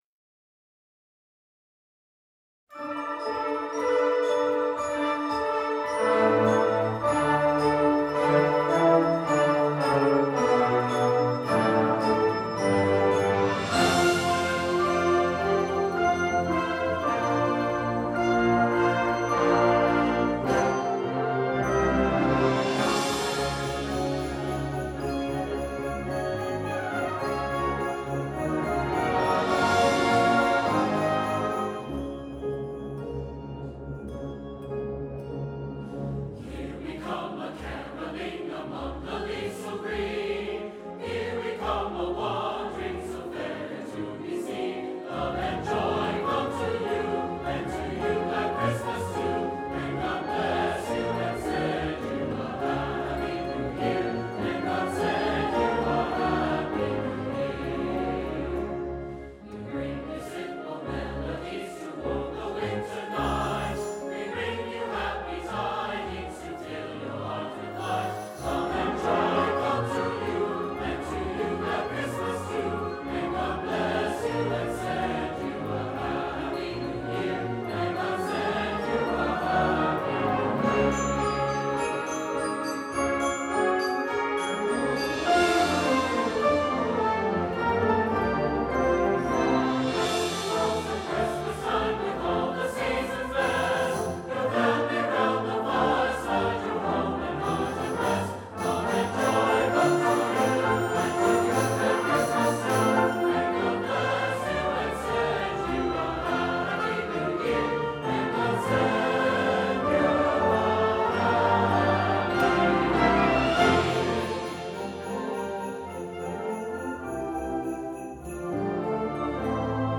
Gattung: Weihnachtswerk mit SATB ad lib.
Besetzung: Blasorchester
und ist genauso fröhlich und festlich wie die Jahreszeit.